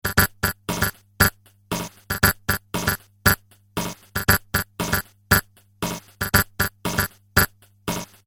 Circuit Bending a TR 626
Rhythm Pattern Patch 2